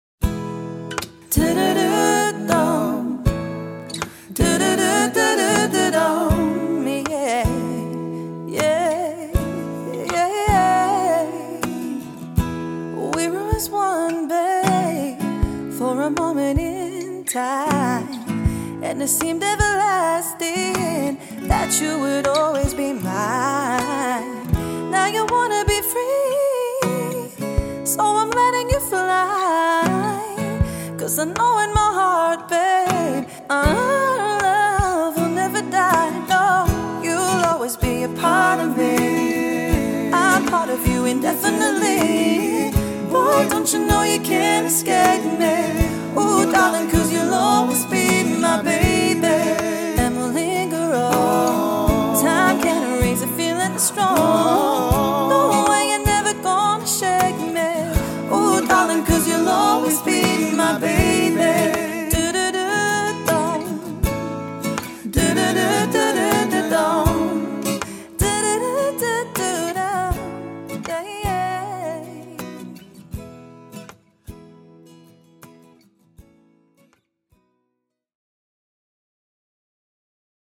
Trio Vocals | Guitar | Looping | DJ
A 90’s cover band!